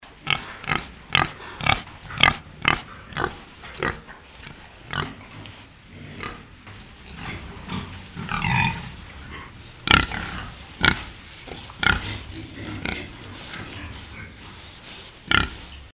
Звуки поросенка